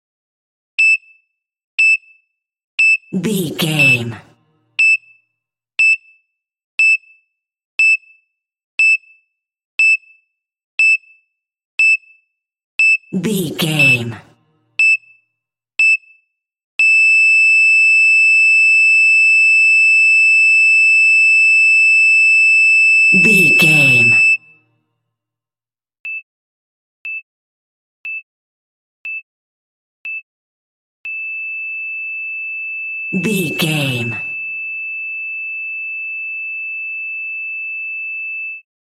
Emergency Heartbeat Monitor Single Beeps Flat 115
Sound Effects
chaotic
anxious